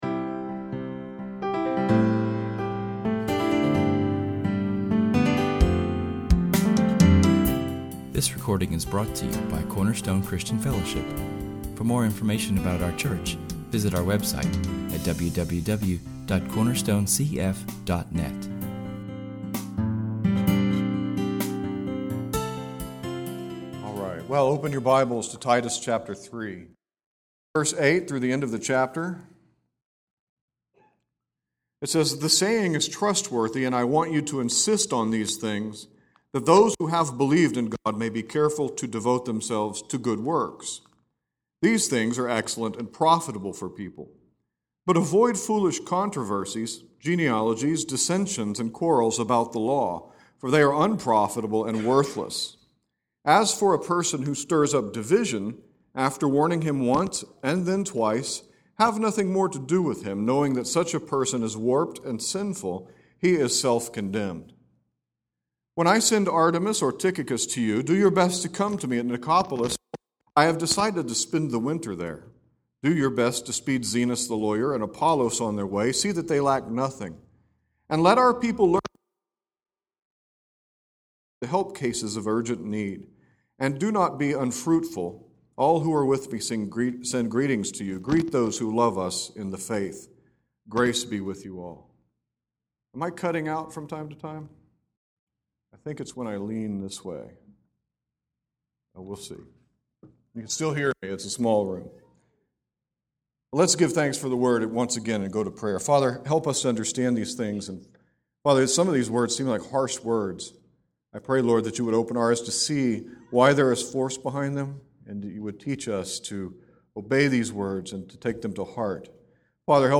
This is our last sermon from the epistle of Titus.